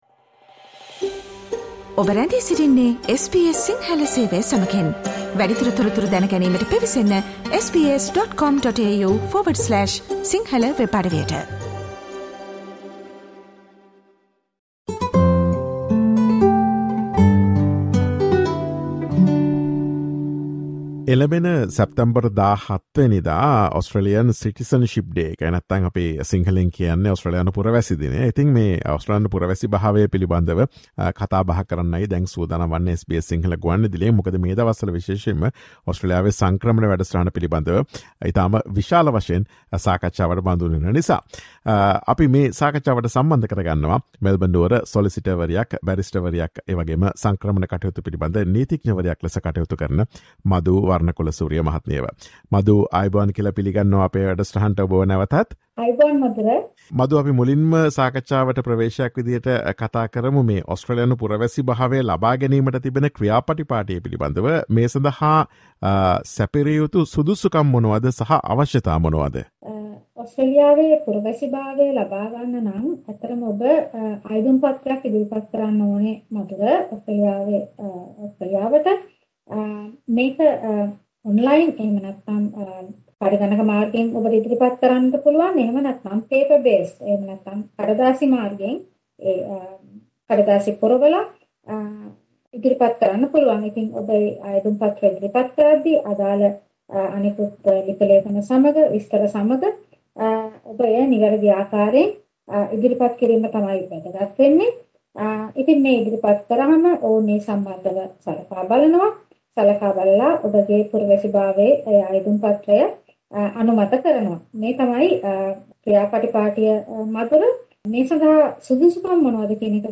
Listen to SBS Sinhala Radio's discussion on Australian citizenship and its benefits.